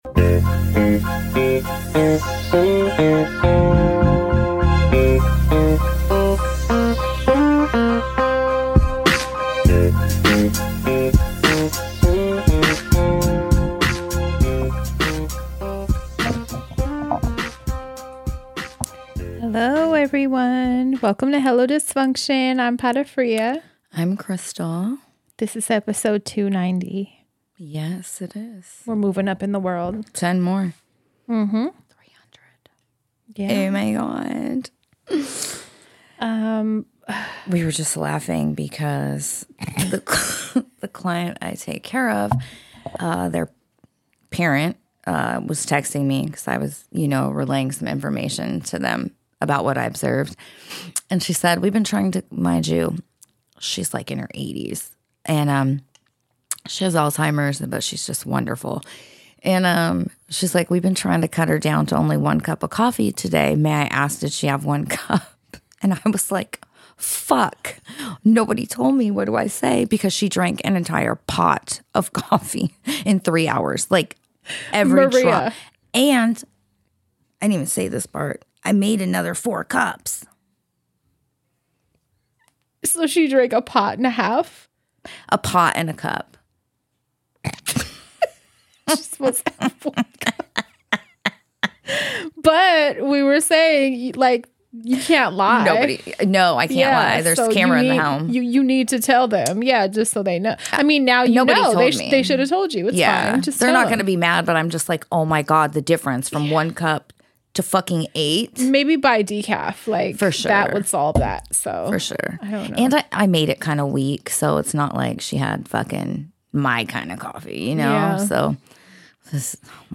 Comedy, Improv